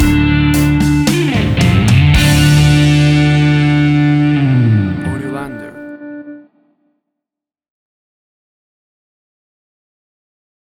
Classic 60s British Blues.
Tempo (BPM): 112